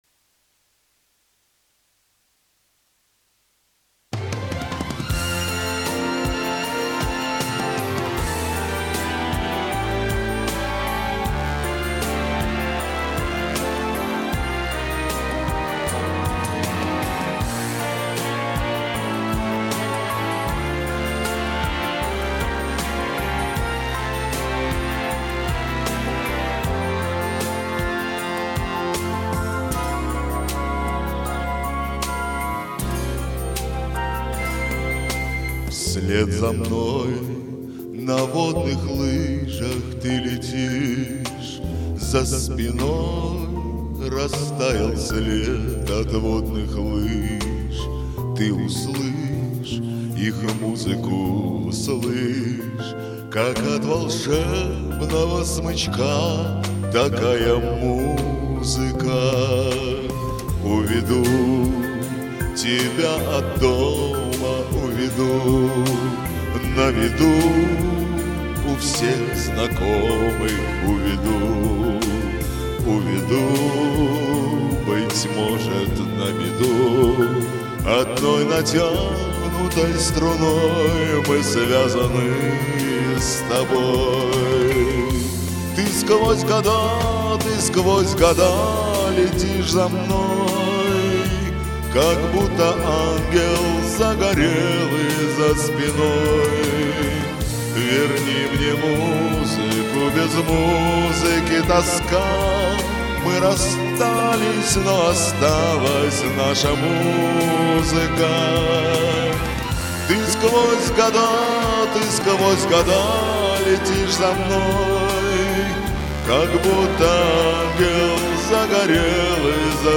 вы оба звучите несколько бедненько, но чистенько